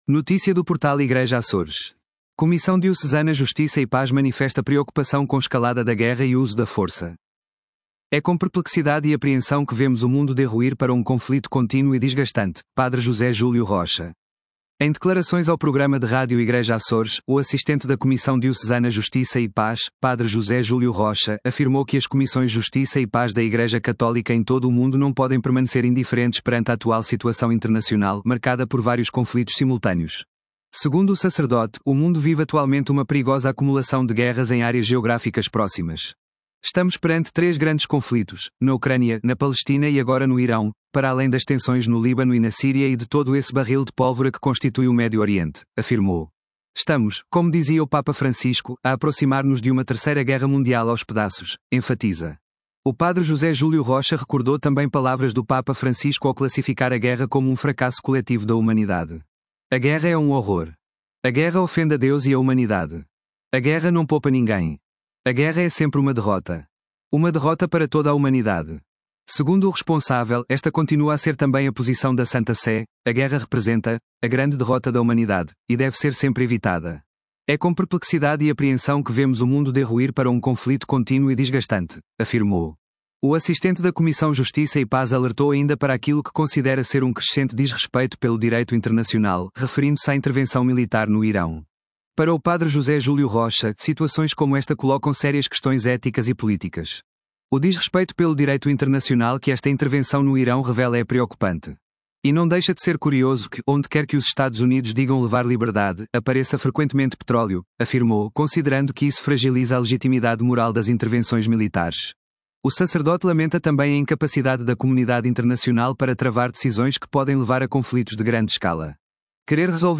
Em declarações ao programa de Rádio Igreja Açores